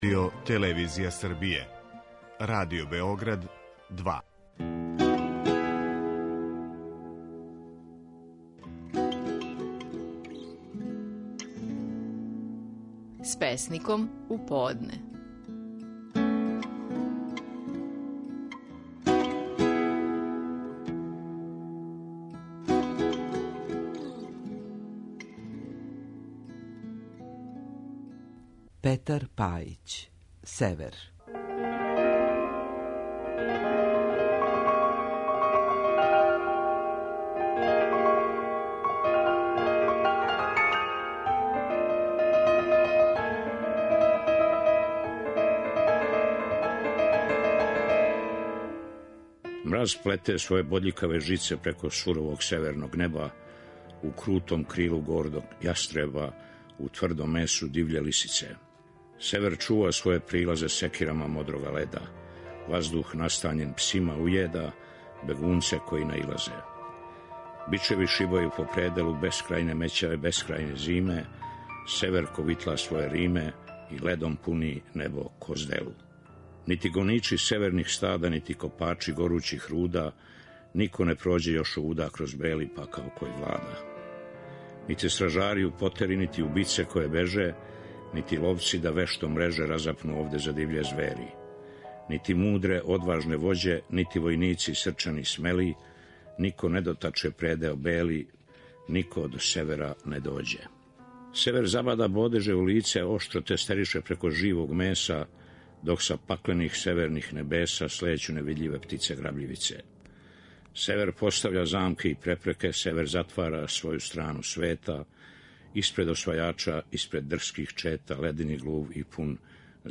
Стихови наших најпознатијих песника, у интерпретацији аутора.
Петар Пајић говори своју песму „Север".